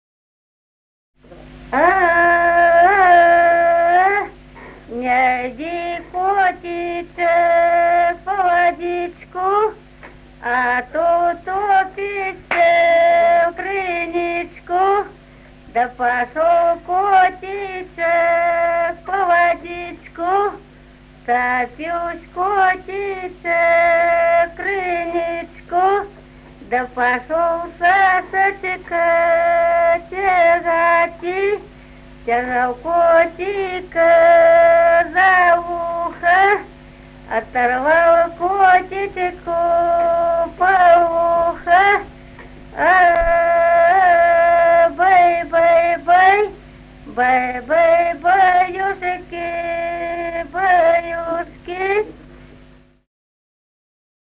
Песни села Остроглядово. Не йди, котичек И 0444-10